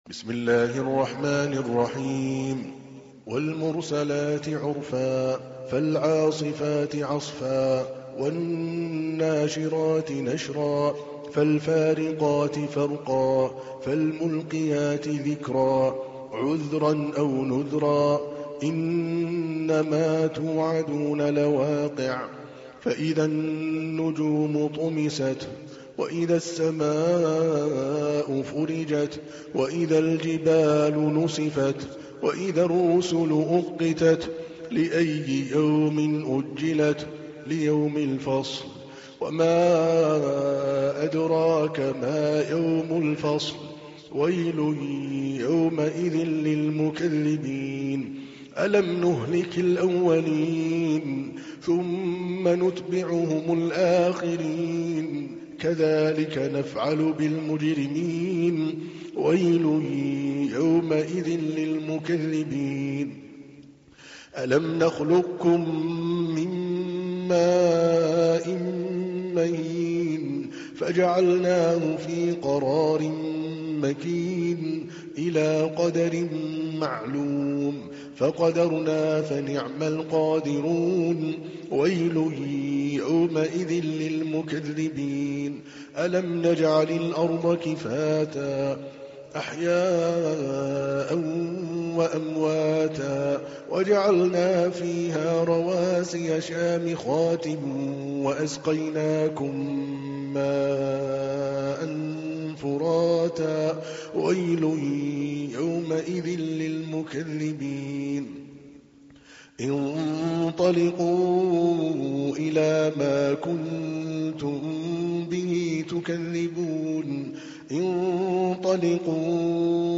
تحميل : 77. سورة المرسلات / القارئ عادل الكلباني / القرآن الكريم / موقع يا حسين